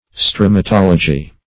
Search Result for " stromatology" : The Collaborative International Dictionary of English v.0.48: Stromatology \Stro`ma*tol"o*gy\, n. [Gr.
stromatology.mp3